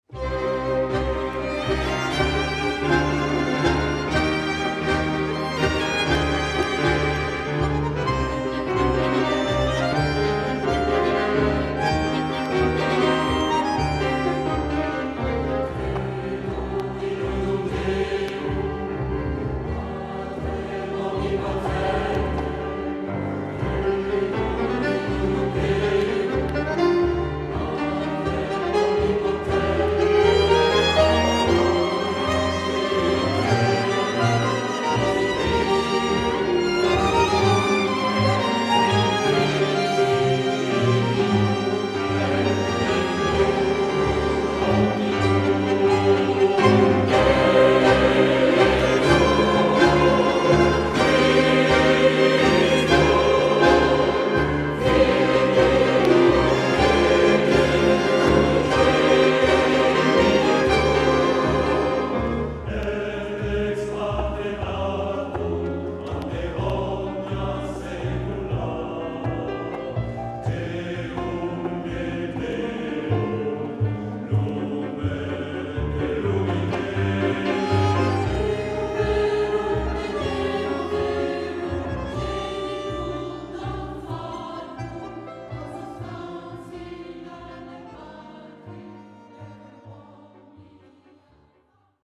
Avec CHOEUR
Messe pour bandonéon; cordes; piano; soprano et choeur